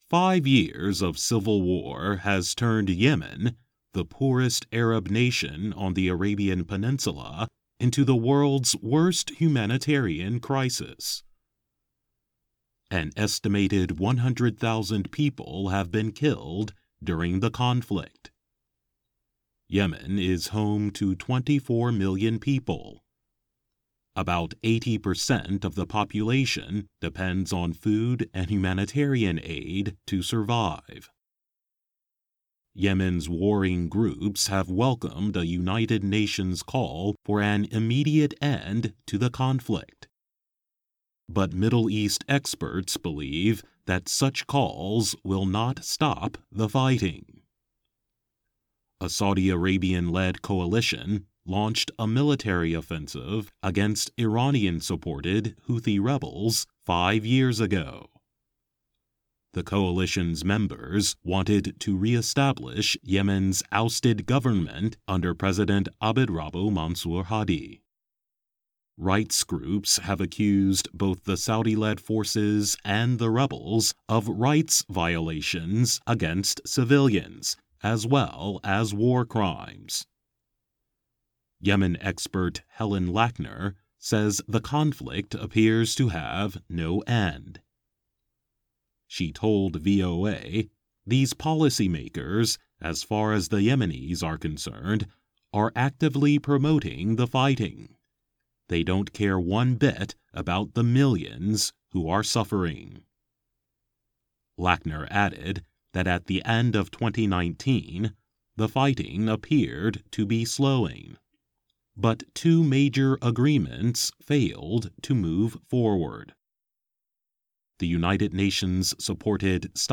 慢速英语:也门专家认为冲突不会结束